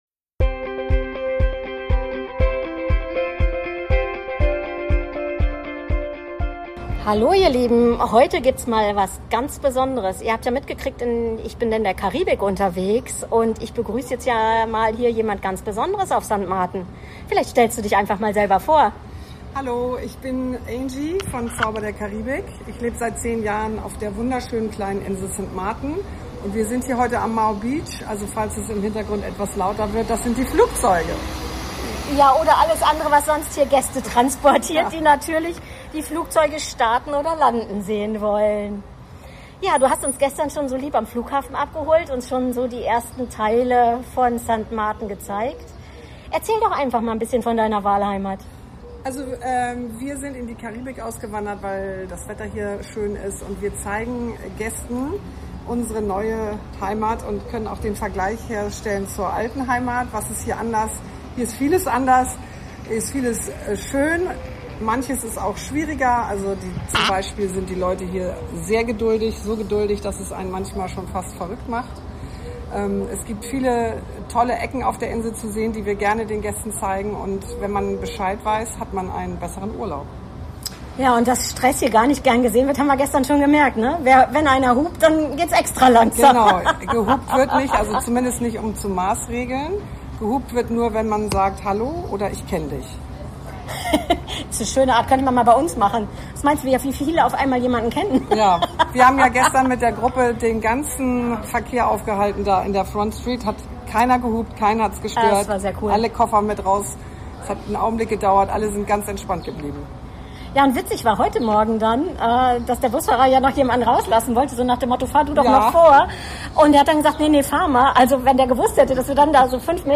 Wir sind für Euch gerade in der Karibik unterwegs und wollen euch ein Stück Paradies für Unterwegs mitgeben! Traumstrände & Wellenrauschen inklusive!